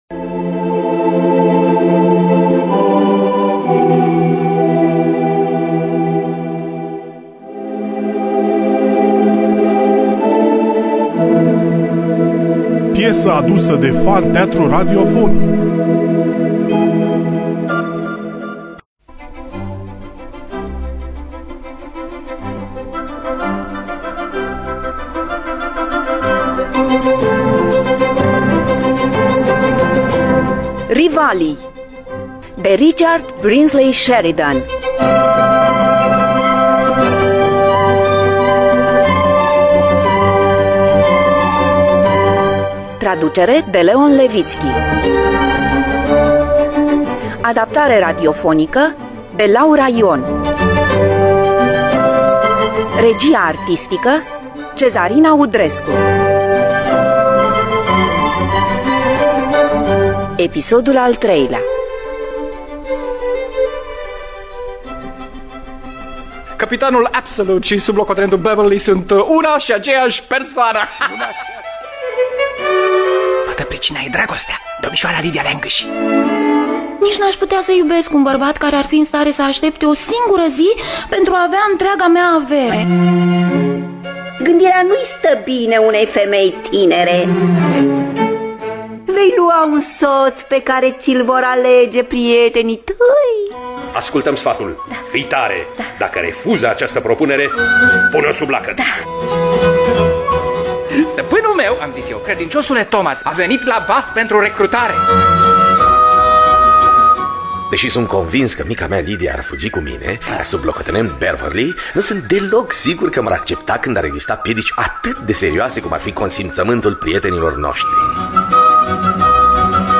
Richard Brinsley Sheridan – Rivalii (2003) – Episodul 3 – Teatru Radiofonic Online